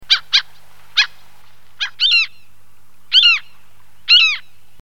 Goéland cendré
Larus canus